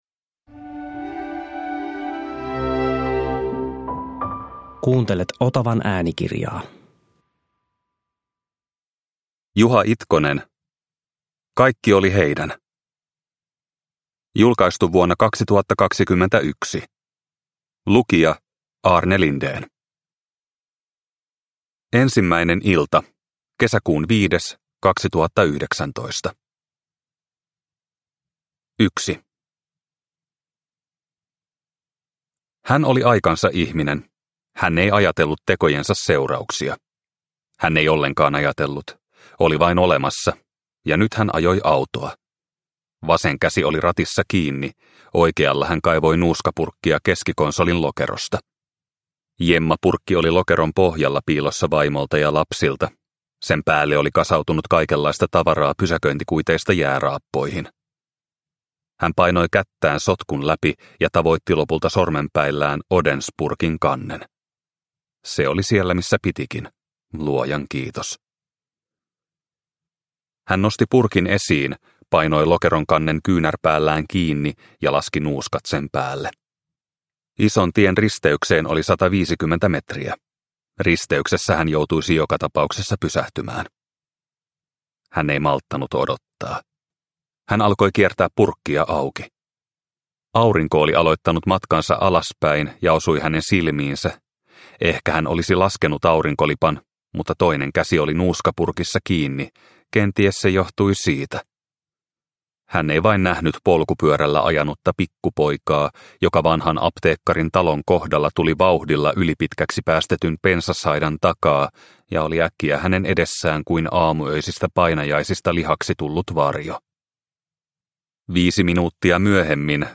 Kaikki oli heidän – Ljudbok – Laddas ner